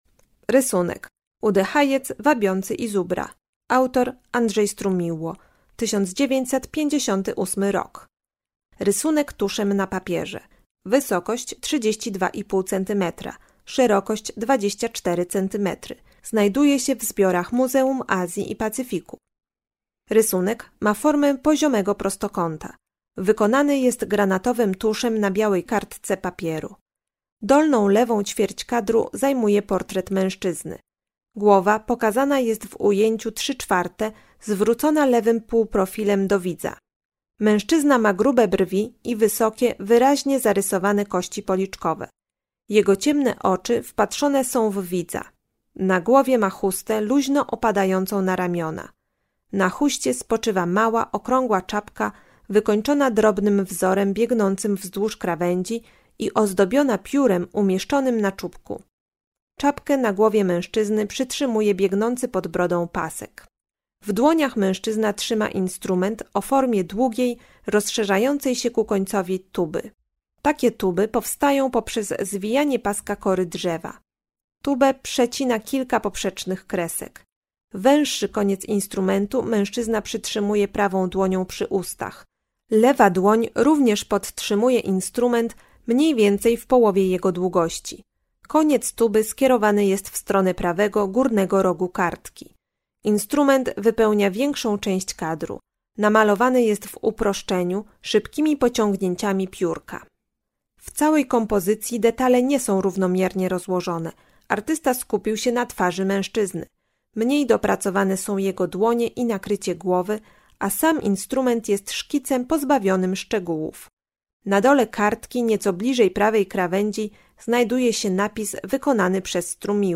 Audiodeskrypcje